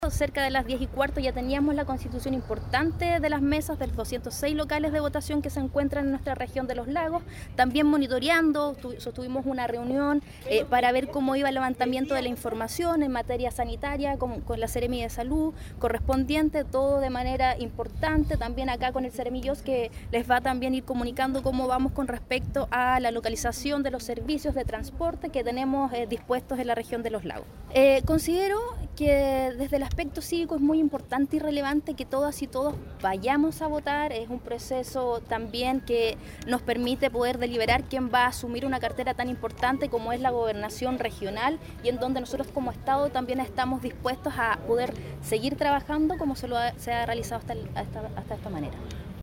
La delegada regional Paulina Muñoz manifestó que la situación durante el día fue seguida atentamente por los organismos públicos, garantizando de esta manera el ejercicio cívico de la comunidad, pese a la complejidad que representa la geografía de la región.